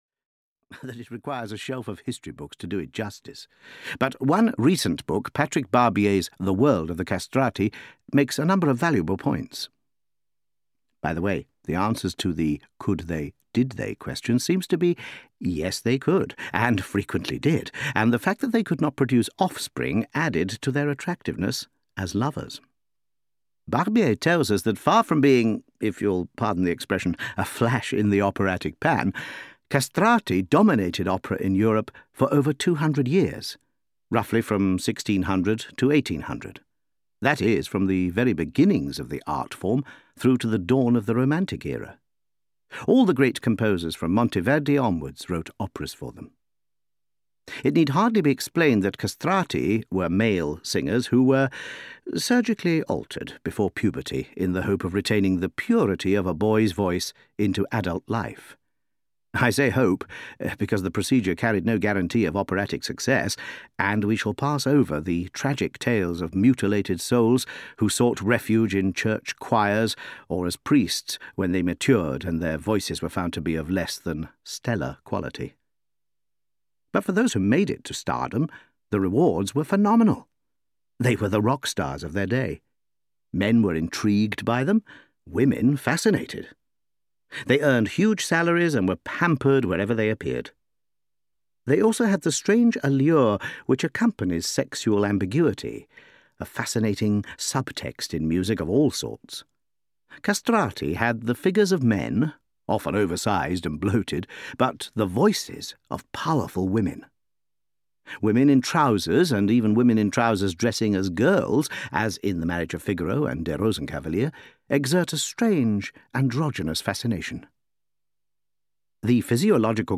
Opera Explained – Orfeo ed Euridice (EN) audiokniha
Ukázka z knihy